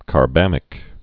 (kär-bămĭk)